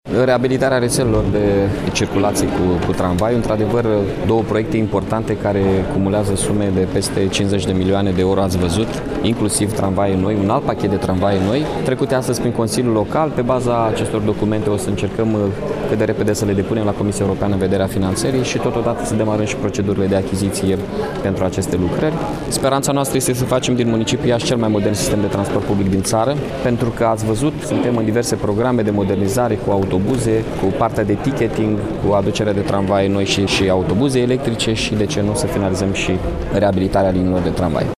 În legătură cu reabilitarea reţelelor de tramvai în Iaşi, primarul Mihai Chirica a explicat pentru postul nostru de radio: